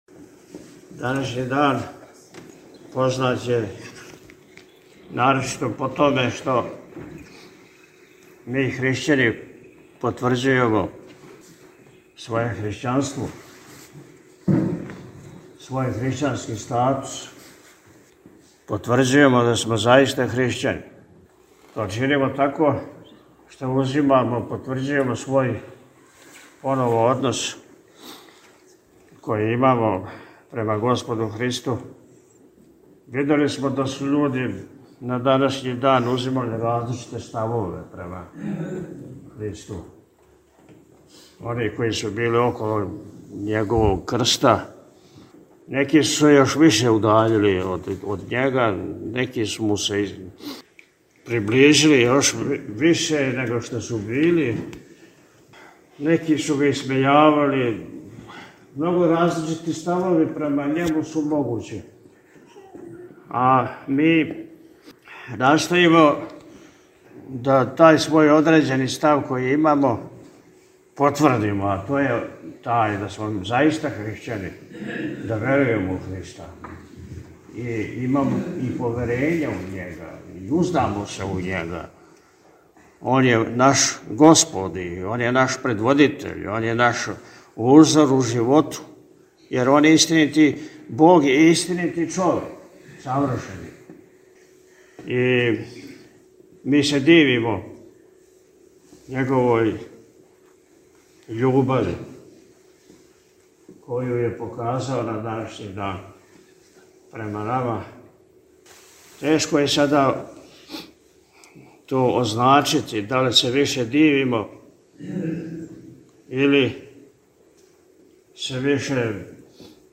На Велики Петак, 18. априла 2025. године, са почетком у 16.00 часова, Његово Високопреосвештенство Архиепископ и Митрополит милешевски г. Атанасије служио је у Вазнесењском храму манастира Милешеве Вечерње са изношењем Плаштанице.
У пастирској беседи, након богослужења, Високопреосвећени је рекао: – Видели смо да су људи на данашњи дан заузимали различите ставове према Христу, они који су били около Његовог Крста.